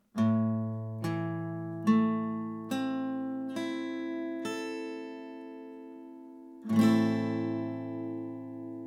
Der a-Moll-Akkord besteht aus den drei Tönen: A, C und E, die auch als Dreiklang bezeichnet werden.
a-Moll (Barré, E-Saite)
A-Moll-Akkord, Barre E-Saite
A-Moll-Barre-E.mp3